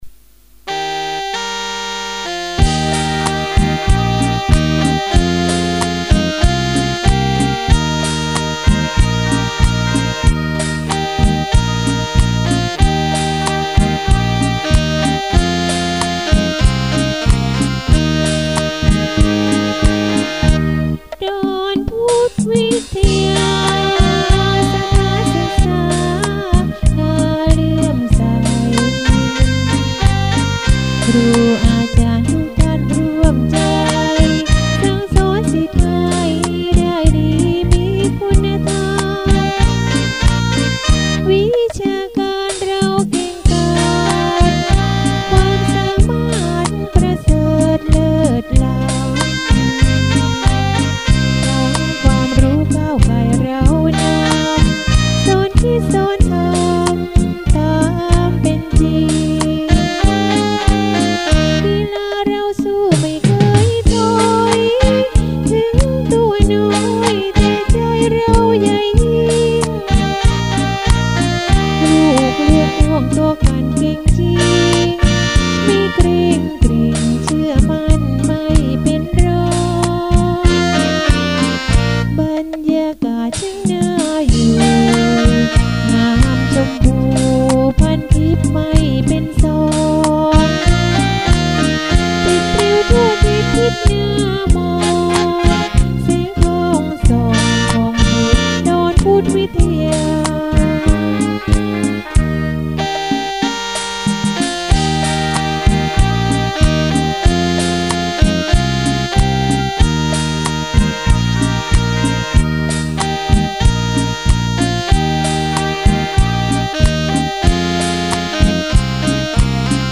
จังหวะ   บีกิน